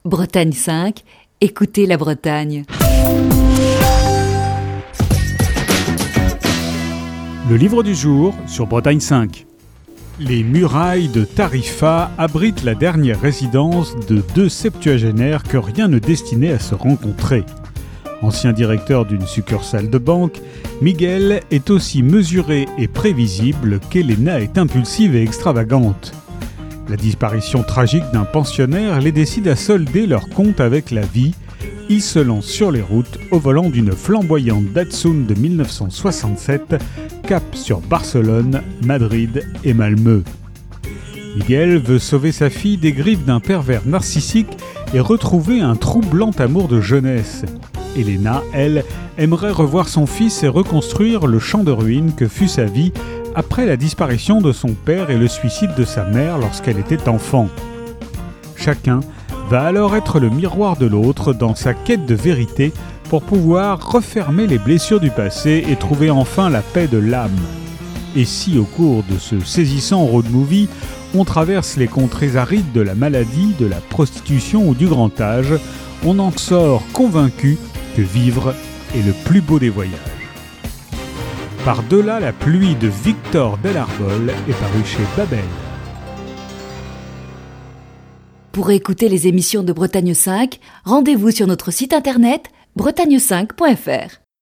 Chronique du 9 avril 2021.